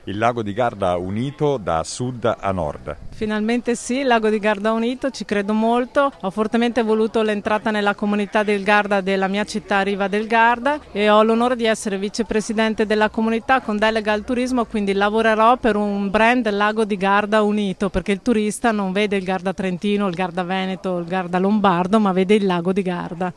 Le nostre interviste agli organizzatori della manifestazione:
Cristina Santi, sindaco di Riva del Garda:
sindaco-riva-del-garda-cristina-santi.mp3